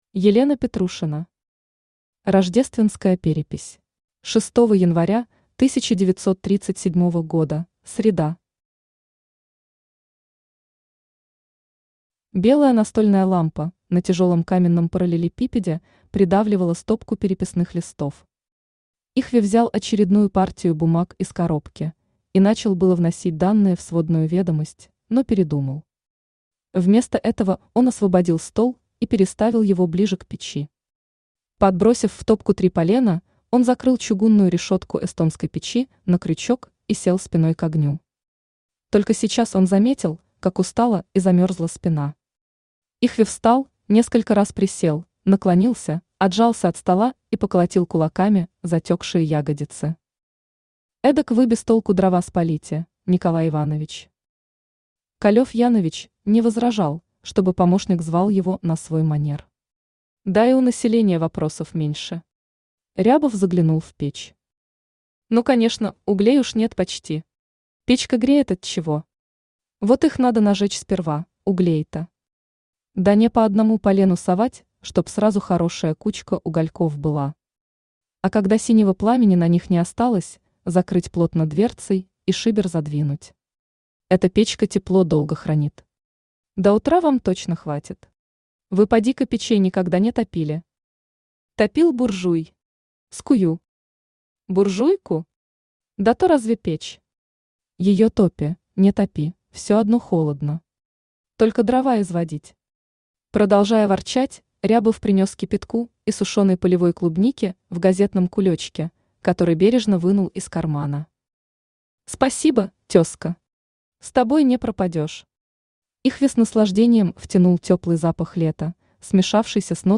Aудиокнига Рождественская перепись Автор Елена Петрушина Читает аудиокнигу Авточтец ЛитРес.